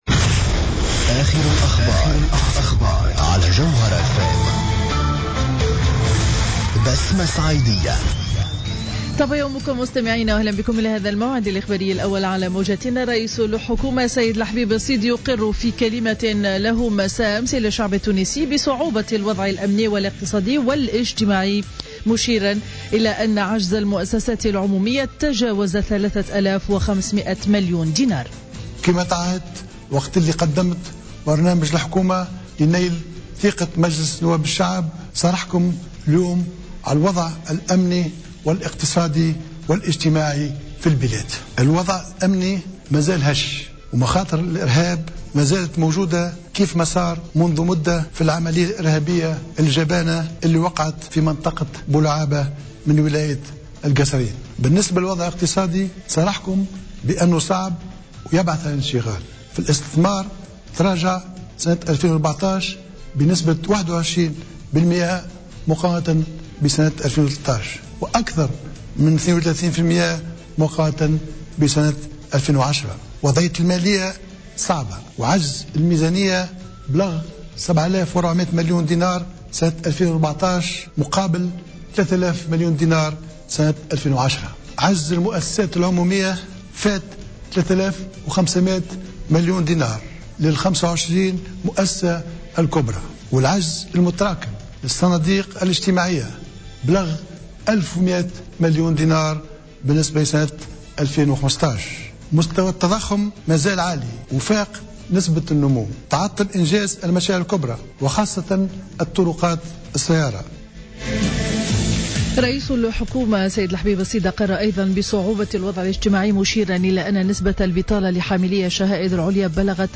نشرة أخبار السابعة صباحا ليوم الثلاثاء 17 مارس 2015